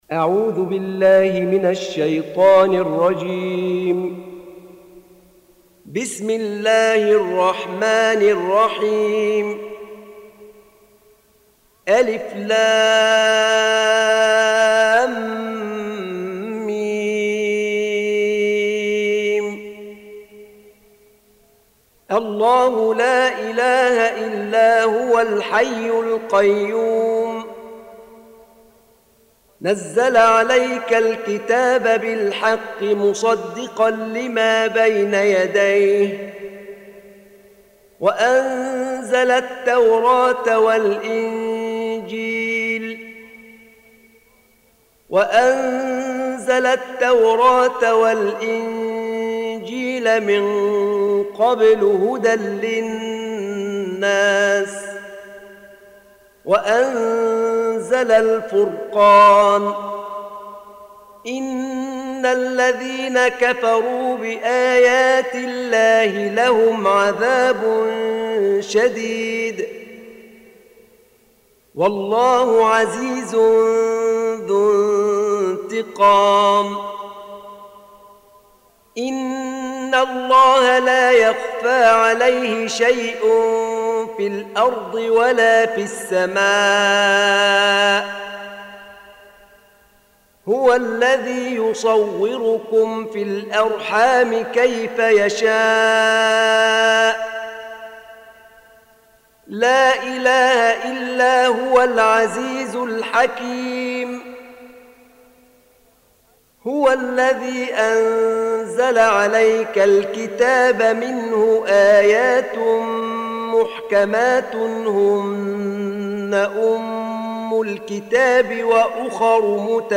3. Surah �l-'Imr�n سورة آل عمران Audio Quran Tarteel Recitation
Surah Sequence تتابع السورة Download Surah حمّل السورة Reciting Murattalah Audio for 3.